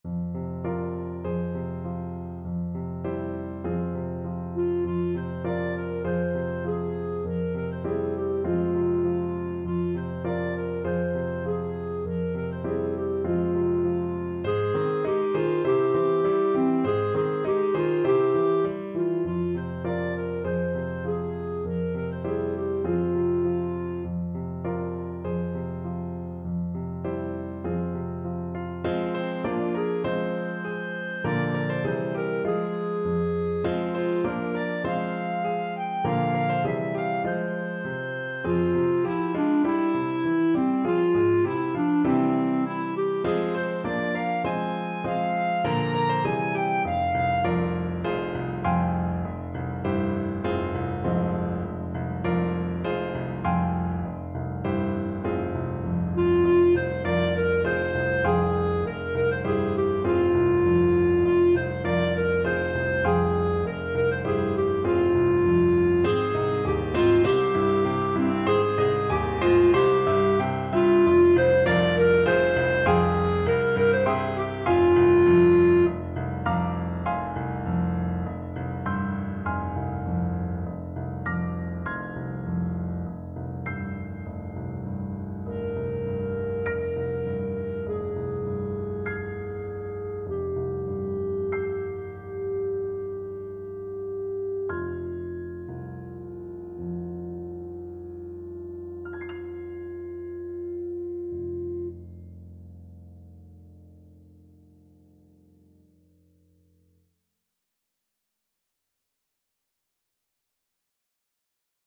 Traditional Trad. Sing We Now of Christmas (Noel Nouvelet) Clarinet version
Clarinet
Traditional Music of unknown author.
Moderato
F minor (Sounding Pitch) G minor (Clarinet in Bb) (View more F minor Music for Clarinet )
2/4 (View more 2/4 Music)